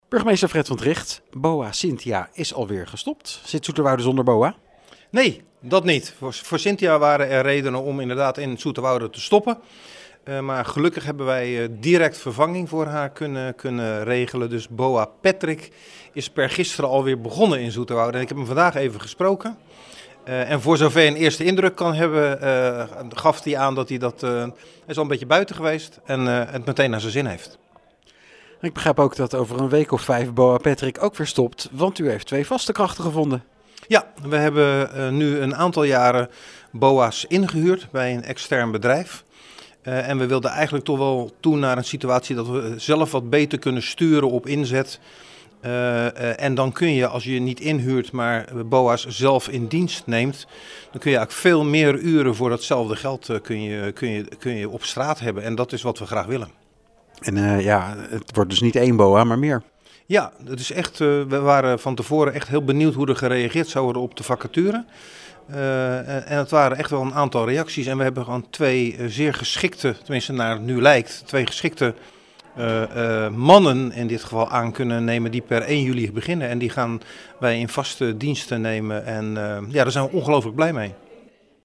in gesprek met burgemeester Fred van Trigt over de twee nieuwe boa’s.